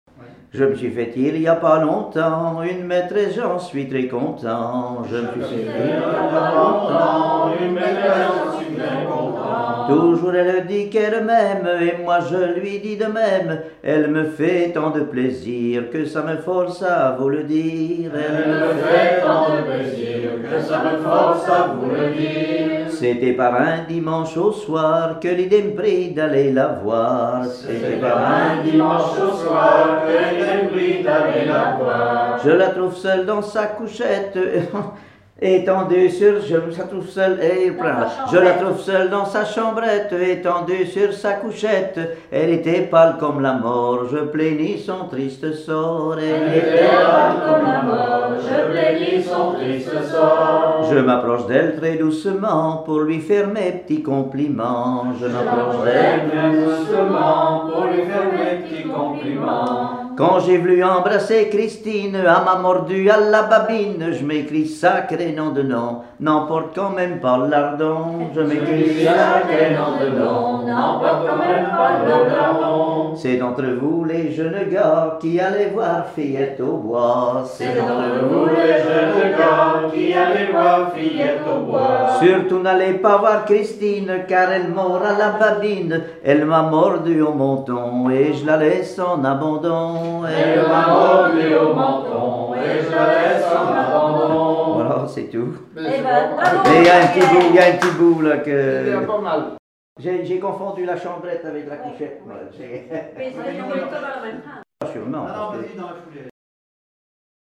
Veillées de chanteurs traditionnels
Pièce musicale inédite